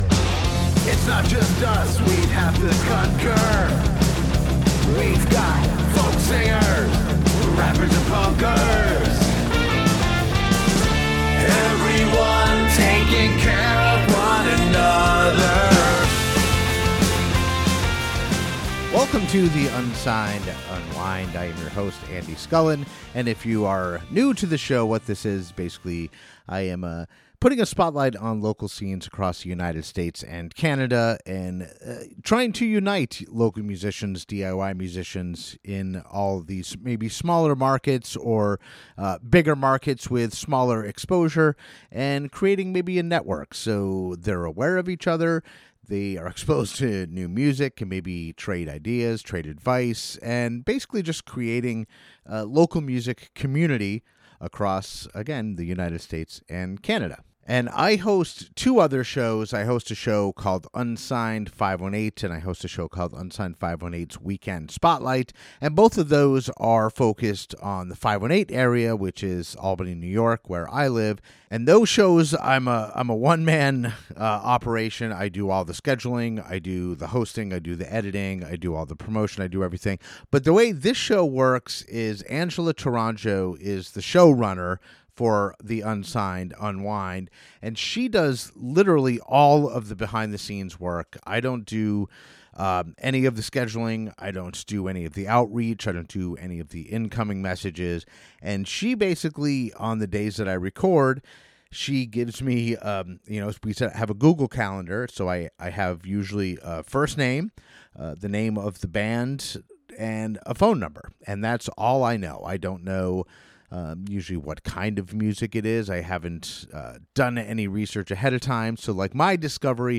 This show is an attempt to gather great local music from all over the US and Canada, have a brief conversation with the band/musician and play one of their songs. My goal is that local-music enthusiasts, such as myself, can discover great local music that otherwise may have remained hidden to them.